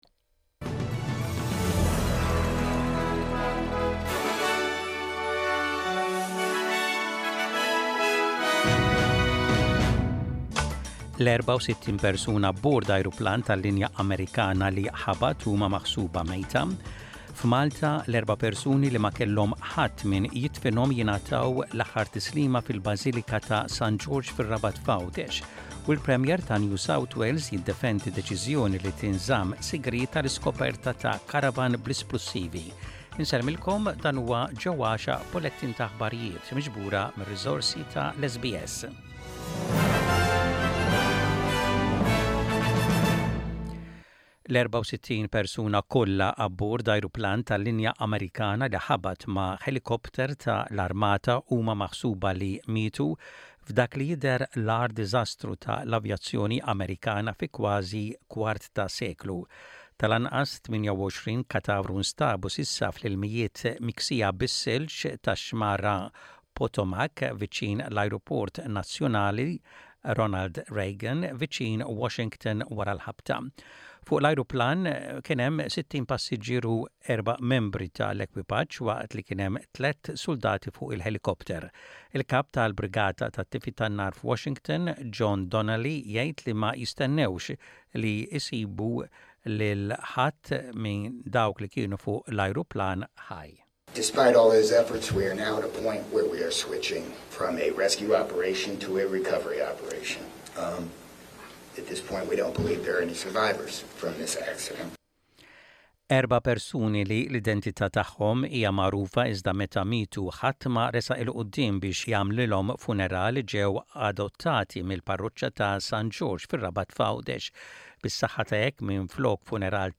Aħbarijiet bil-Malti: 31.01.25